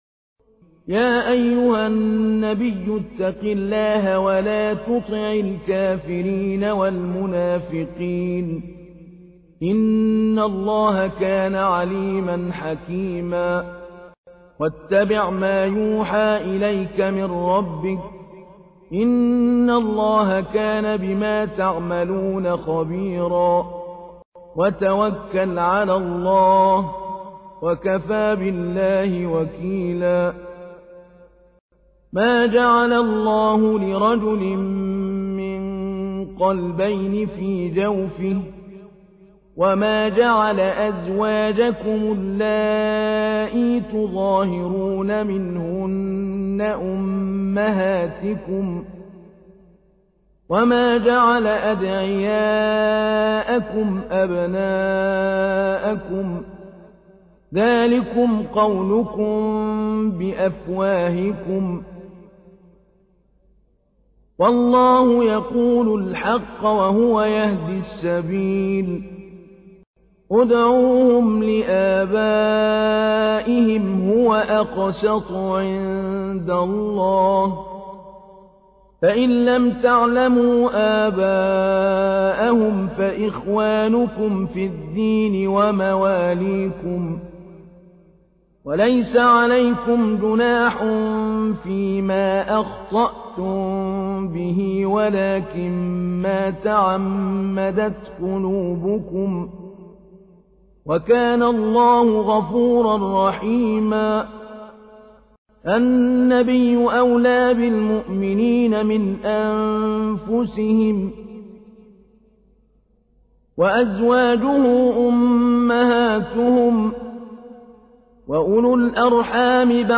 تحميل : 33. سورة الأحزاب / القارئ محمود علي البنا / القرآن الكريم / موقع يا حسين